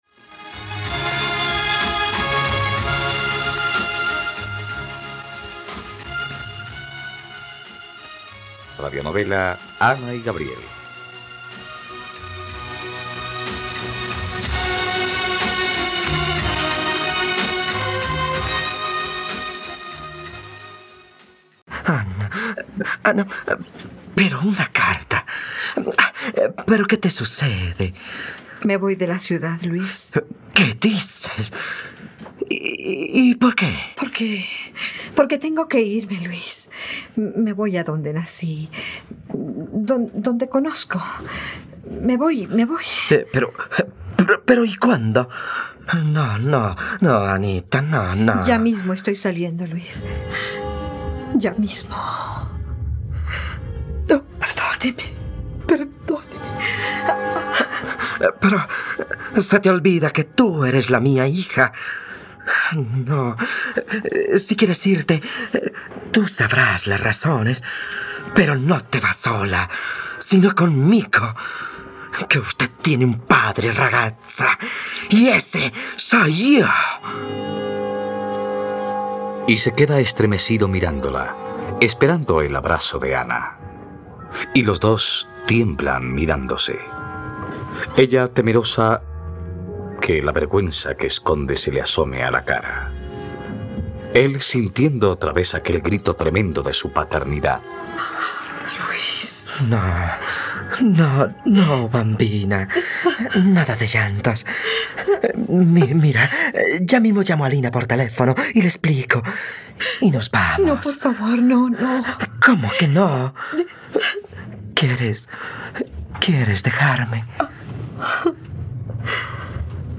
..Radionovela. Escucha ahora el capítulo 104 de la historia de amor de Ana y Gabriel en la plataforma de streaming de los colombianos: RTVCPlay.